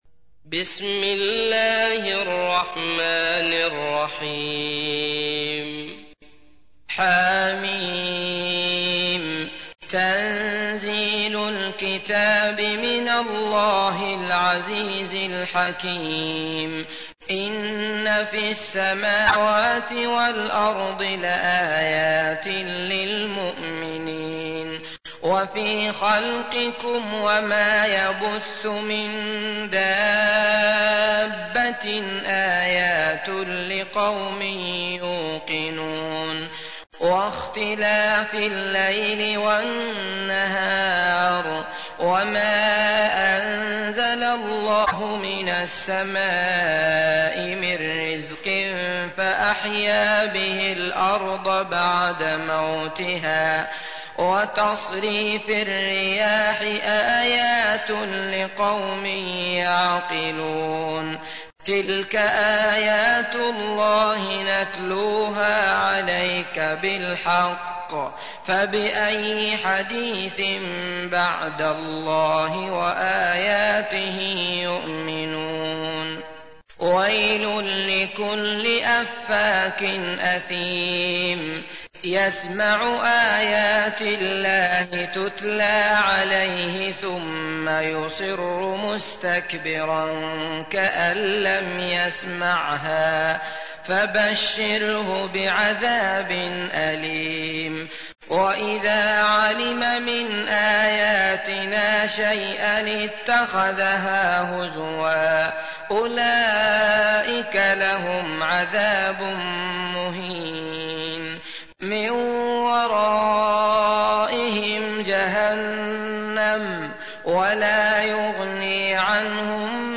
تحميل : 45. سورة الجاثية / القارئ عبد الله المطرود / القرآن الكريم / موقع يا حسين